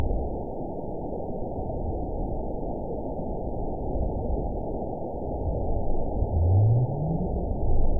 event 912527 date 03/28/22 time 22:03:41 GMT (3 years, 1 month ago) score 9.67 location TSS-AB04 detected by nrw target species NRW annotations +NRW Spectrogram: Frequency (kHz) vs. Time (s) audio not available .wav